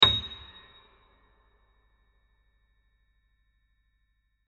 A#6